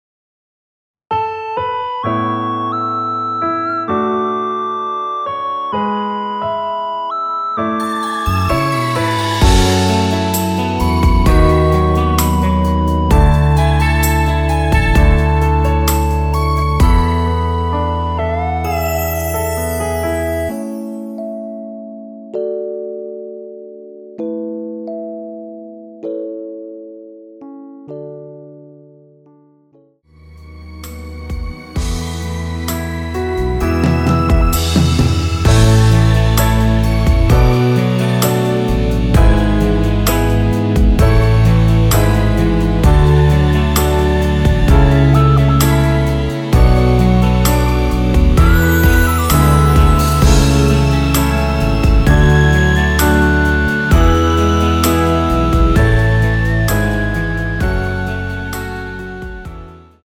원키에서(-1) 내린 MR 입니다.
◈ 곡명 옆 (-1)은 반음 내림, (+1)은 반음 올림 입니다.
앞부분30초, 뒷부분30초씩 편집해서 올려 드리고 있습니다.
중간에 음이 끈어지고 다시 나오는 이유는